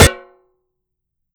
Hit_Metal 03.wav